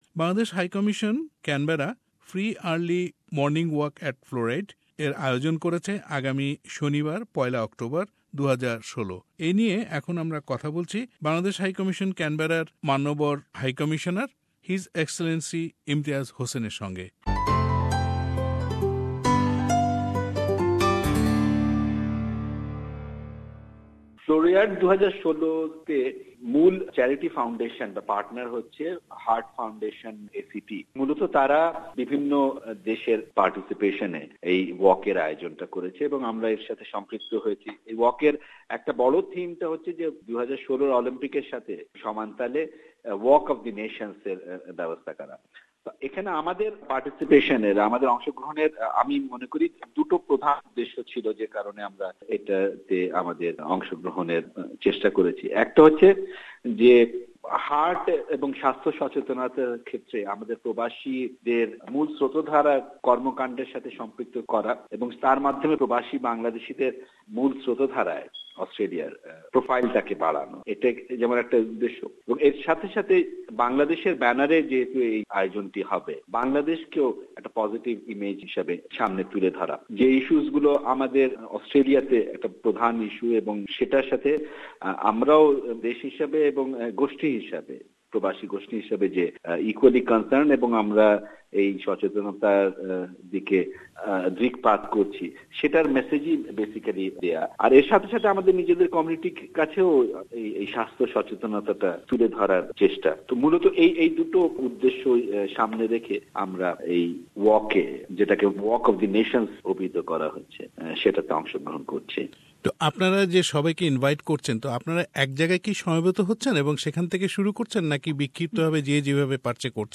Early Morning Walk at Floriade : Interview with HE Kazi Imtiaz Hossain
HE Kazi Imtiaz Hossain , High Commisioner , Peoples Republic of Bangladesh Source: SBS Bangla